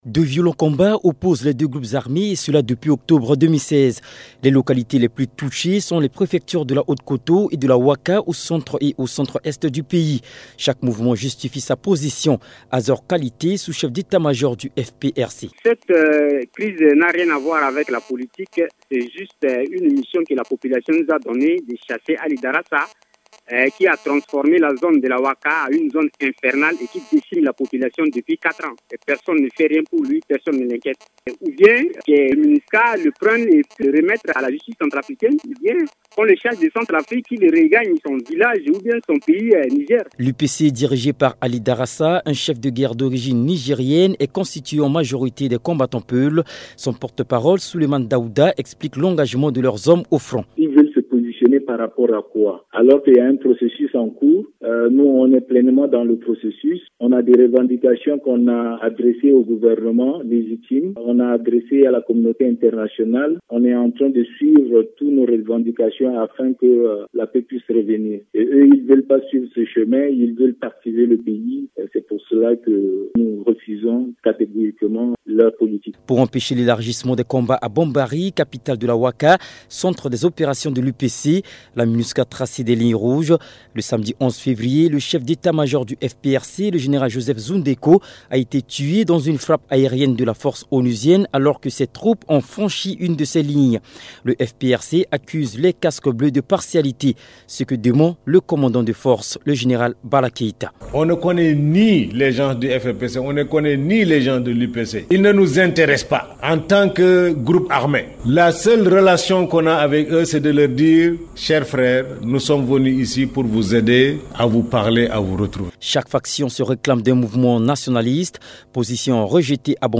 Reportage
à Bangui